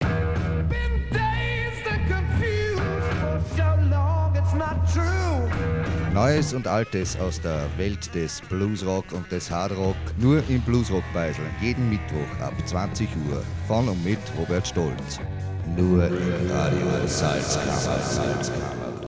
Sendungstrailer
FRS-TRAILER-BLUES-ROCK-BEISL.mp3